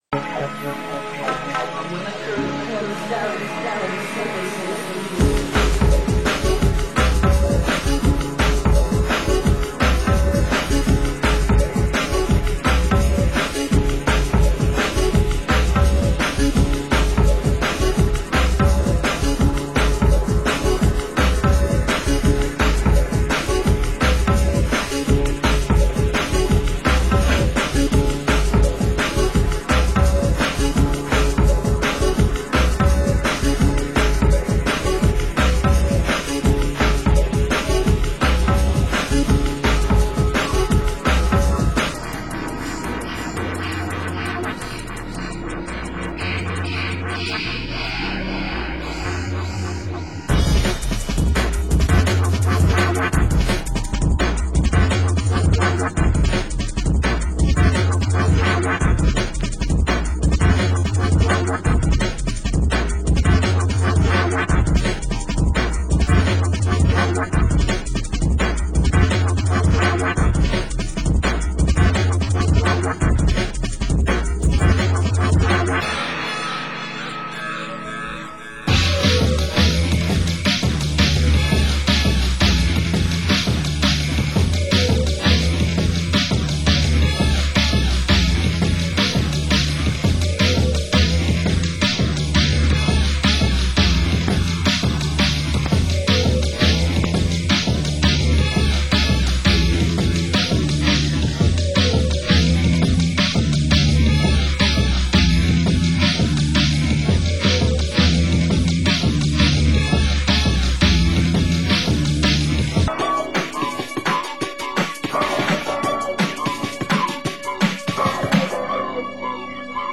Genre Drum & Bass